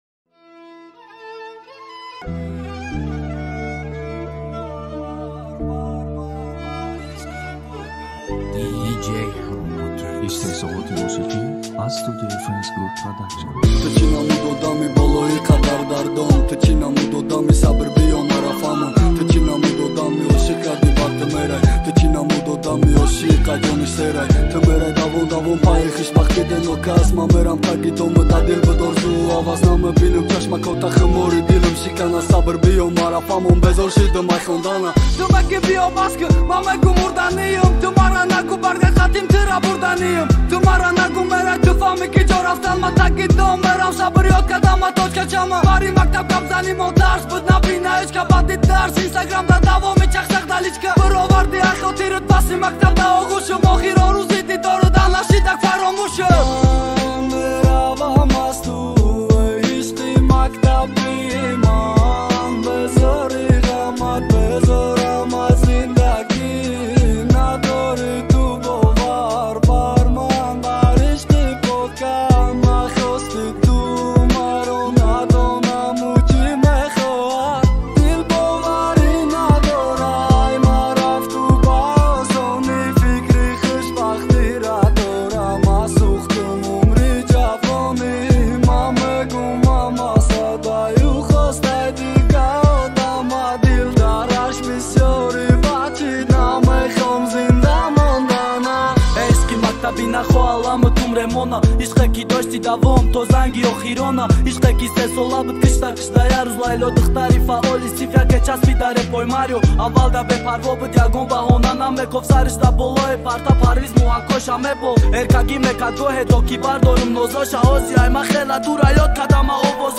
Узбекские песни